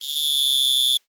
cricket_chirping_solo_03.wav